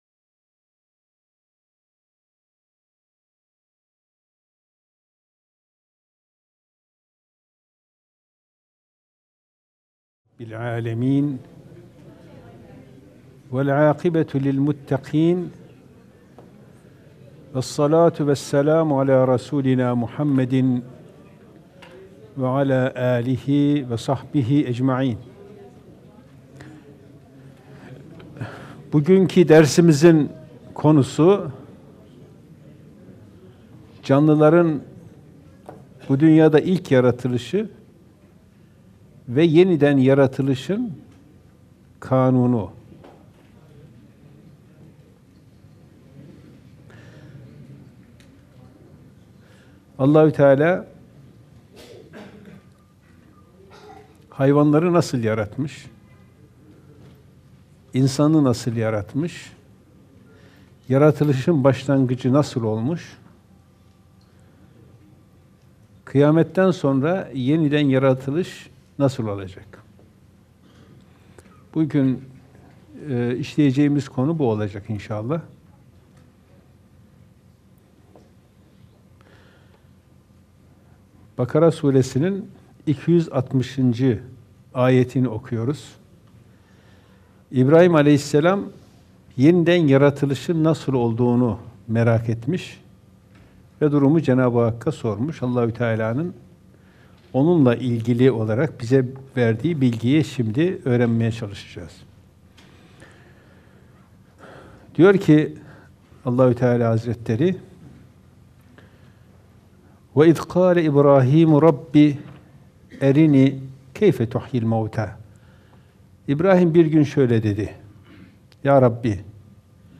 Kur'an Sohbetleri